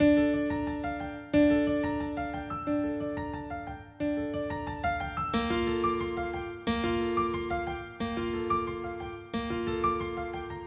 原声钢琴 90bpm Dminor
描述：D小调的原声钢琴循环曲...
Tag: 90 bpm Hip Hop Loops Piano Loops 1.79 MB wav Key : D